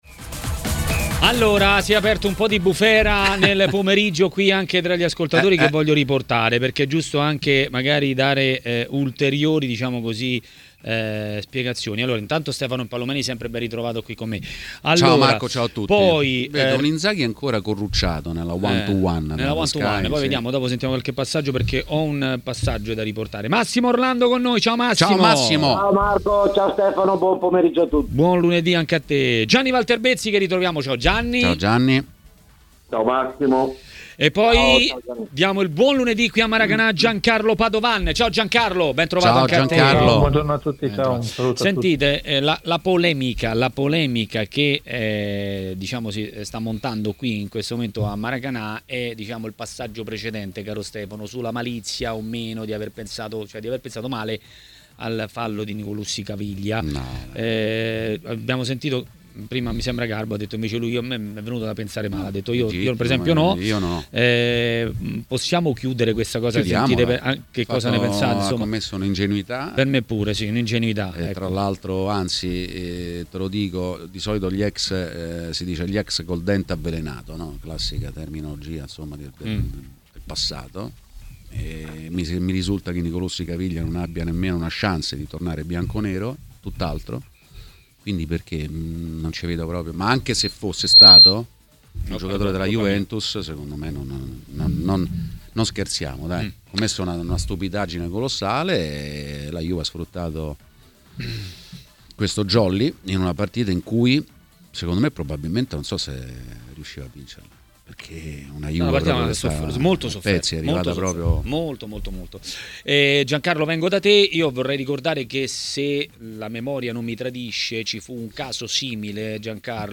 Ascolta l'audio Nel corso di Maracanà, trasmissione di TMW Radio, è intervenuto il giornalista